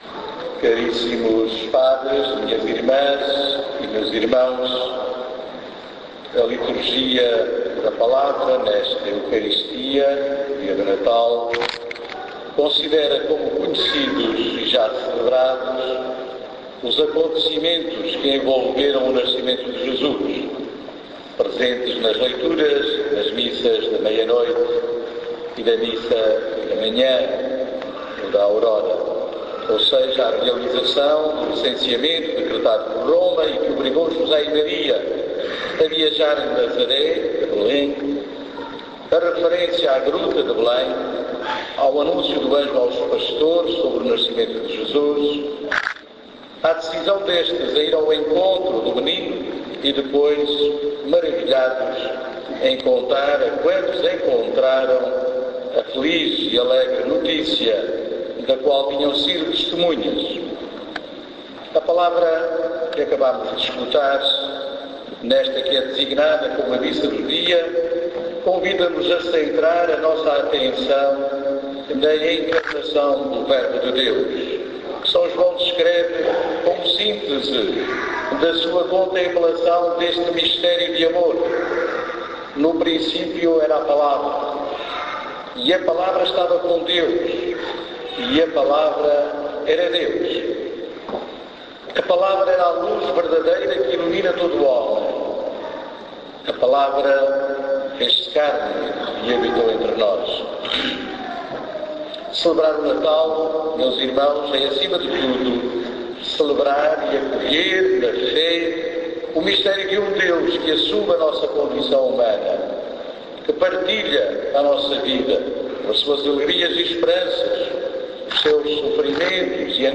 Homilia_dia_natal_2013.mp3